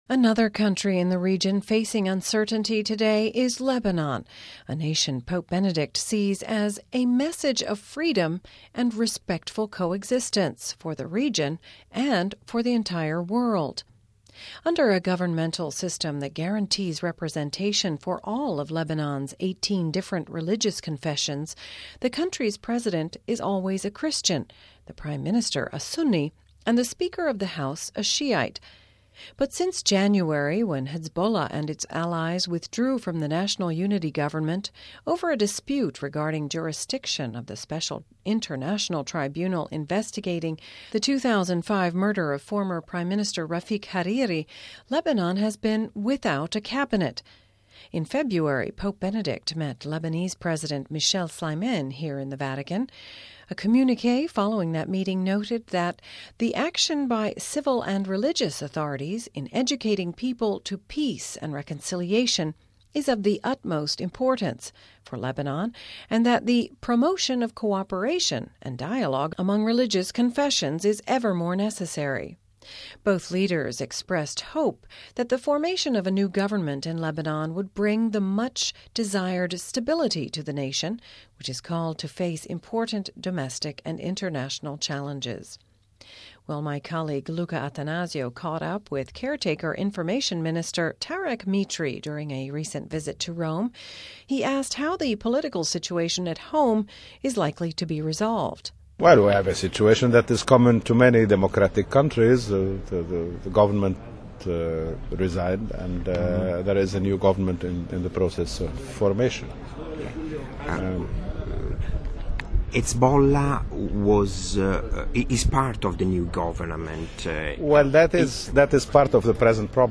Vatican Radio caught up with caretaker Information minister Tarek Mitri during a recent visit to Rome. We asked him how the political situation at home is likely to be resolved.